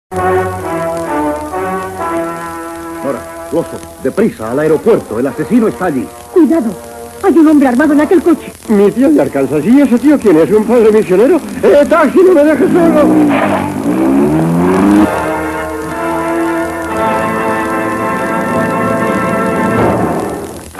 Fragment d'una escena en un cotxe
Ficció